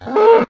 cowhurt1.ogg